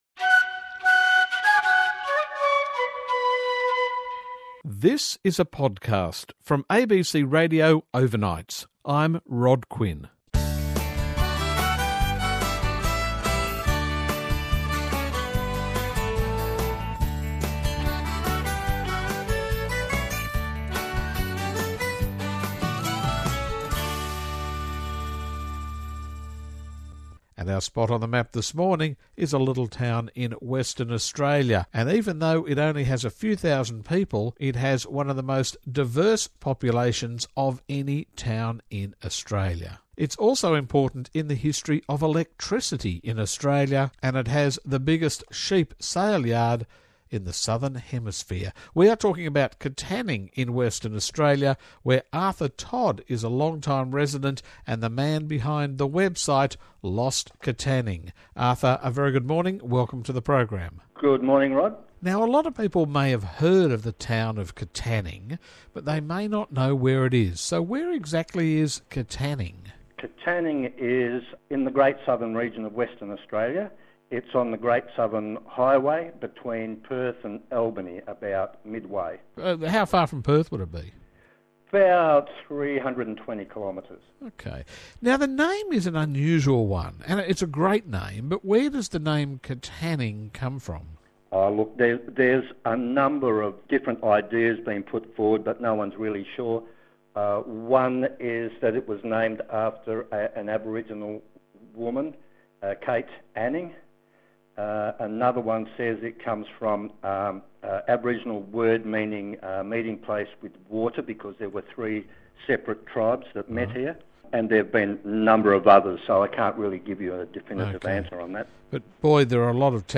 LOST KATANNING ON RADIO